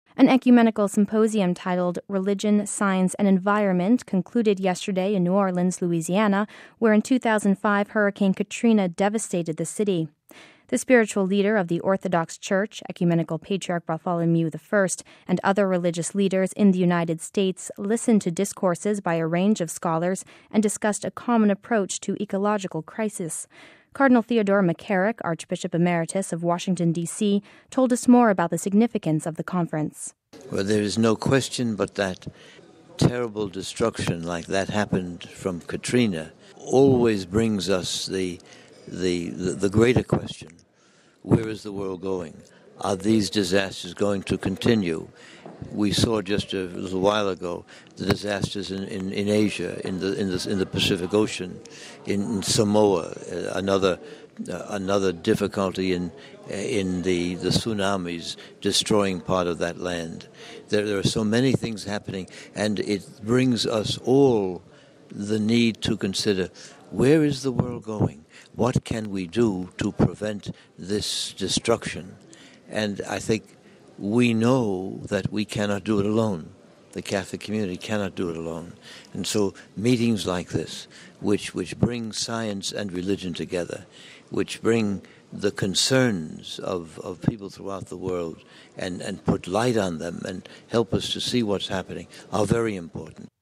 The spiritual leader of the Orthodox Church, Ecumenical Patriarch Bartholomew I, and other religious leaders in the United States listened to discourses by a range of scholars, and discussed a common approach to ecological crises. Cardinal Theodore McCarrick, Archbishop Emeritus of Washington, DC, told us more about the significance of the conference.